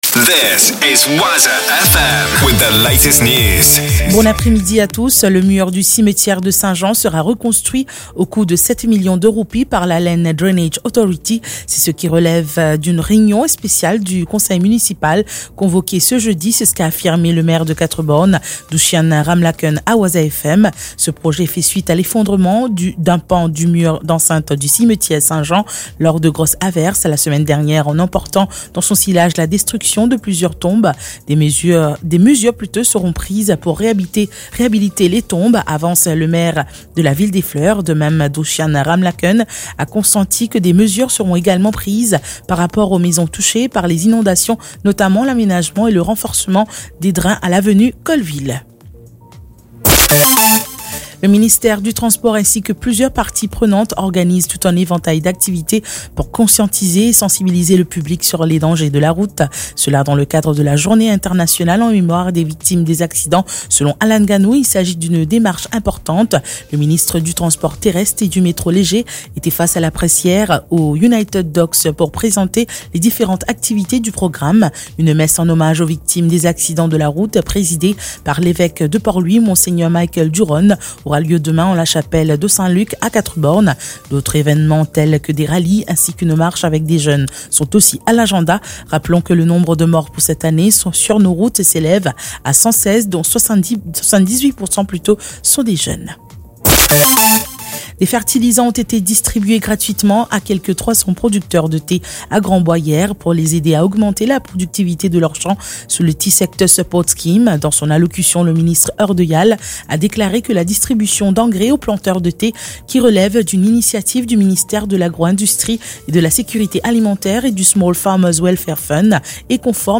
NEWS 15H - 17.11.23